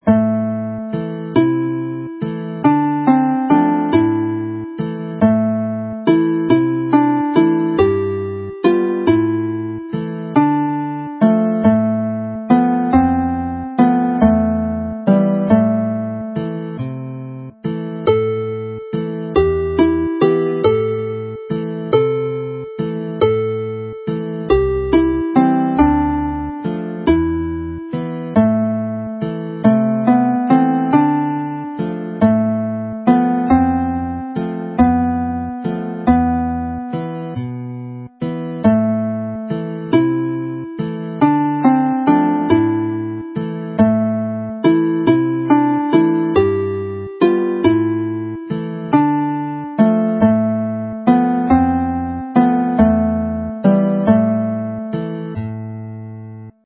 The set closes in 6/8 time with Tua Beth'lem Dref (On to Bethlehem Town) with words written by William Evans (bardic name Wil Ifan)  set to music with the ABA structure.
Play the melody slowly